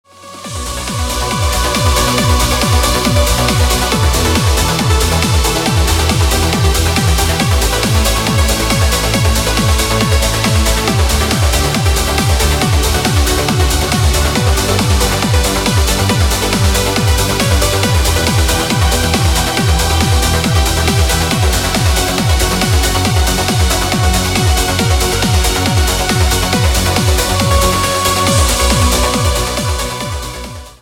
без слов
Trance
Epic Trance